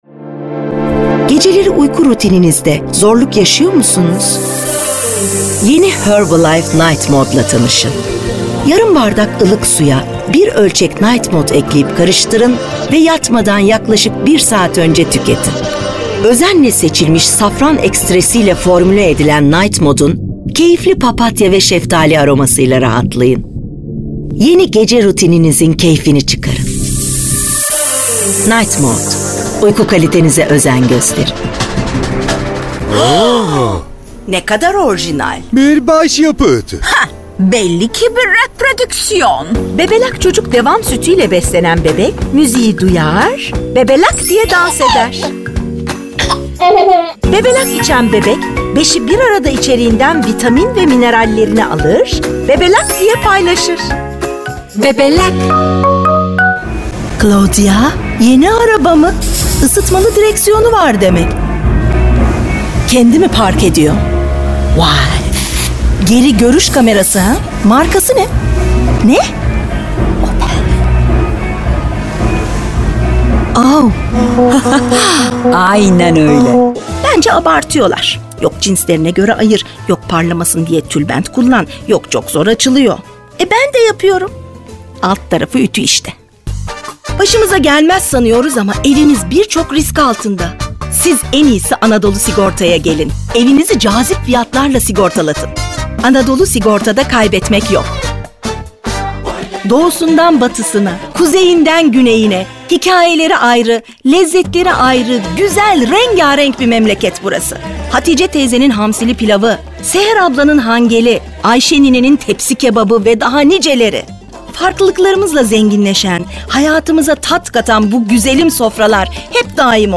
KATEGORİ Kadın
DEMO SESLERİ
Belgesel, Canlı, Eğlenceli, Sakin, Güvenilir, Havalı, Karakter, Animasyon, Şefkatli, Promosyon, Sıcakkanlı, Film Sesi, Dış Ses, Dostane,